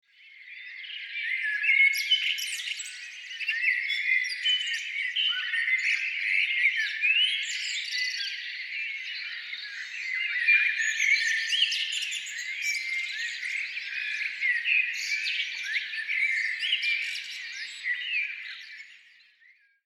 In vier eleganten Frontfarben erhältlich, sorgt sie per Bewegungssensor für 2 Minuten beruhigendes Vogelgezwitscher – ideal für kleine Auszeiten im Alltag.
Das fröhliche Zwitschern von Amseln und anderen Singvögeln füllt den Raum für 120 Sekunden – genau lang genug für eine kleine mentale Pause, einen bewussten Atemzug oder eine Mini-Meditation zwischendurch.
Bewegungsmelder reagiert auf Lichtveränderung, Vogelgezwitscher der Amsel
ZwitscherBox-sound-sample.mp3